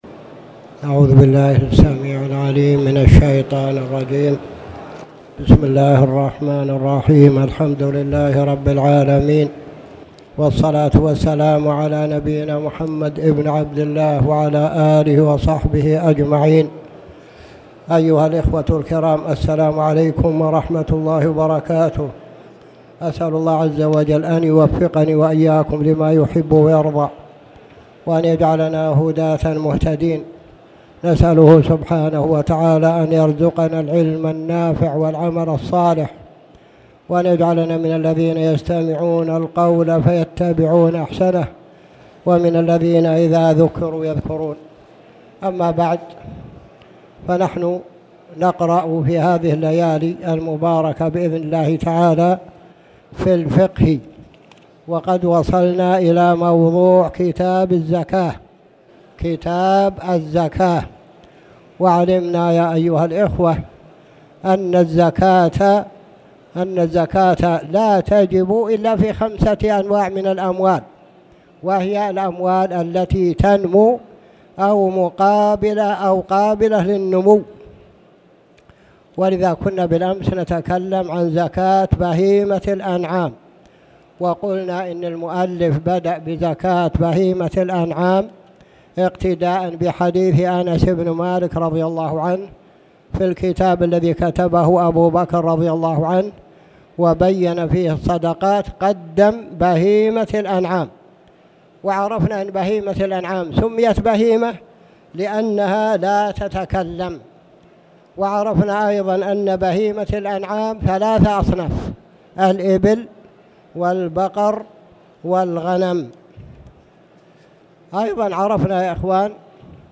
تاريخ النشر ١٣ جمادى الآخرة ١٤٣٨ هـ المكان: المسجد الحرام الشيخ